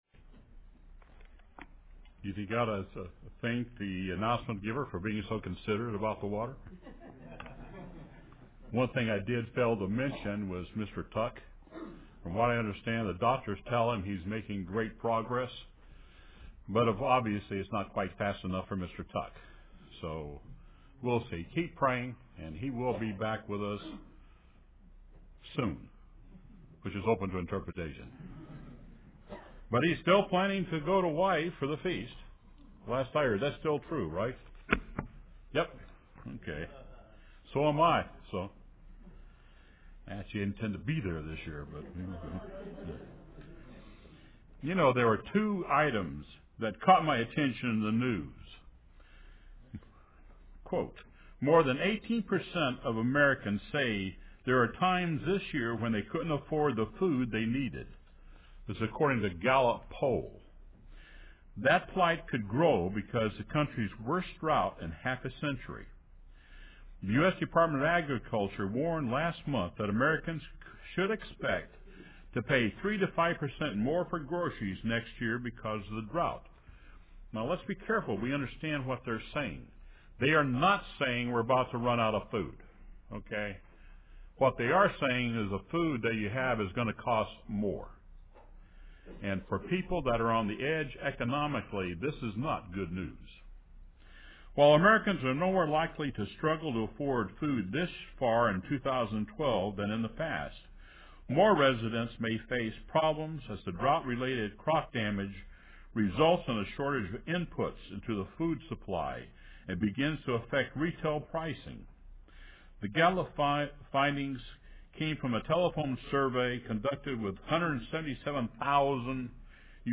Here is a partial listing of scriptures cited in today's sermon.